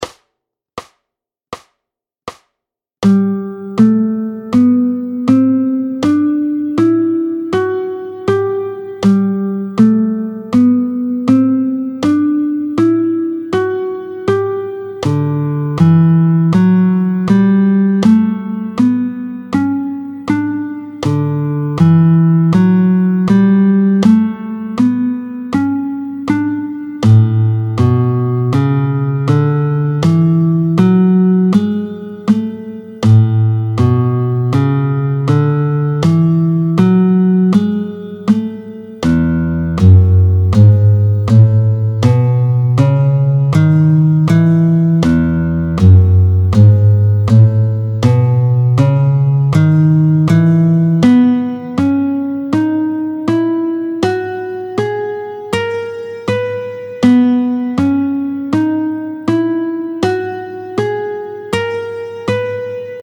Luth
09-05 Les gammes en dièses, Sol, Ré, La, Mi, Si…, tempo 80